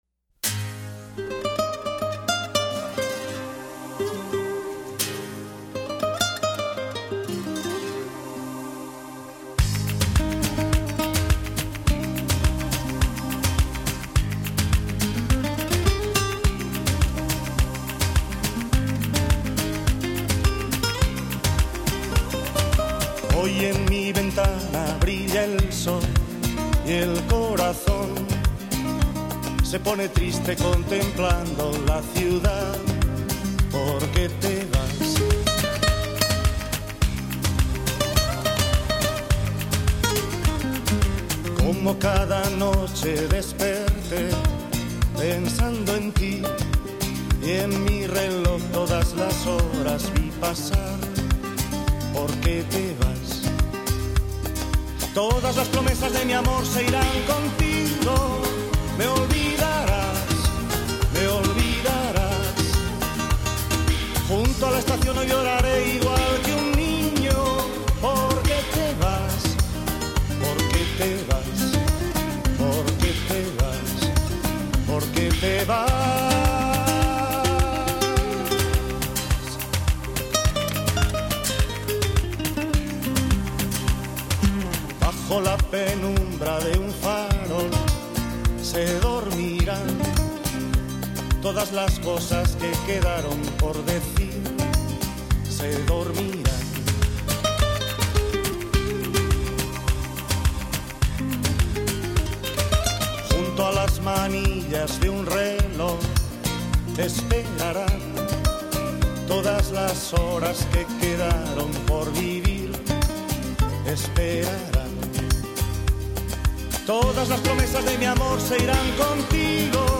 латино